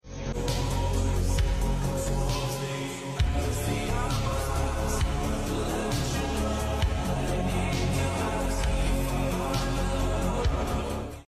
Black Chevrolet Camaro (sorry Sound Effects Free Download